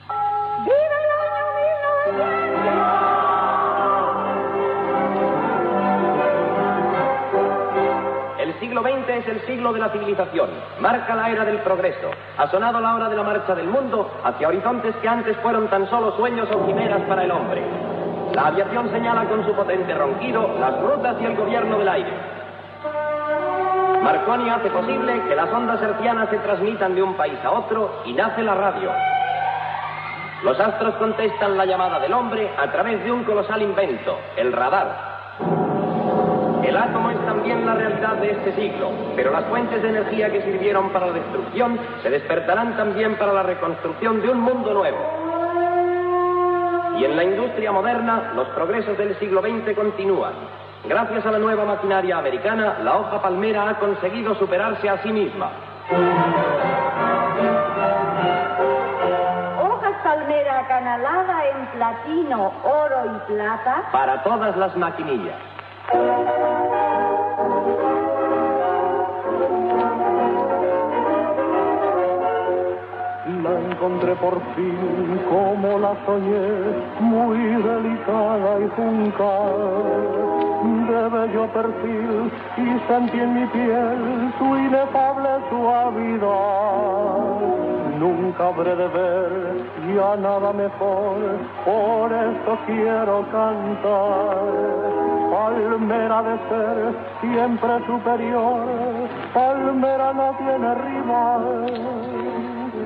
Publicitat d'Hoja Palmera "acanalada de oro, platinum y plata" . Després de fer una llista de grans invents del segle XX, tipus de fulles d'afeitar i cançó del producte
Fragment extret del programa "La radio con botas" emès per Radio 5, l'any 1991.